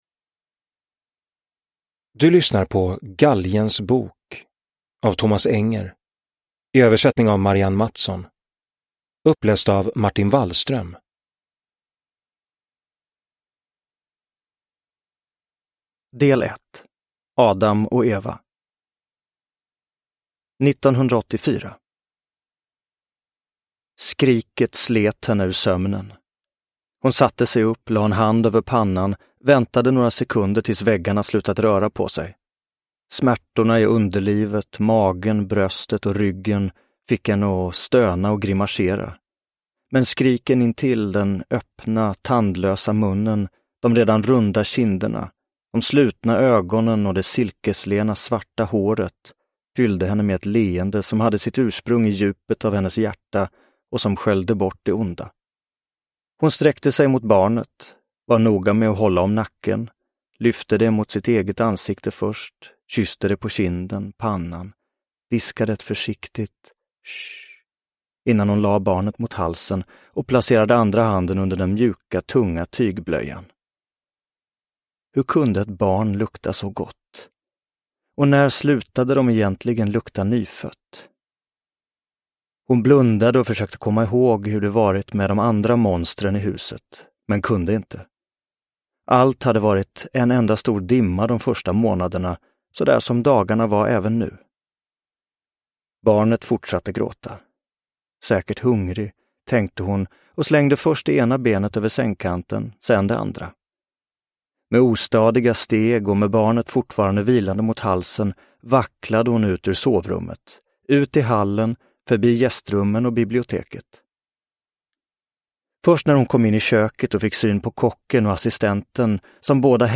Galgens bok – Ljudbok – Laddas ner
Uppläsare: Martin Wallström